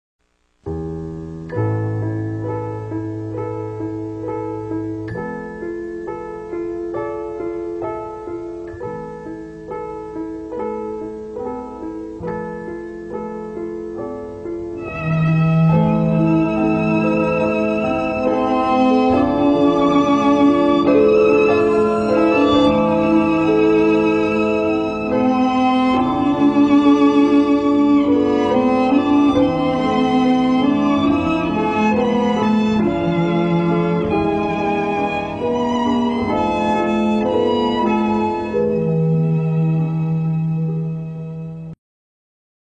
Une composition originale pour la musique du film
Bande Originale du film
violoncelliste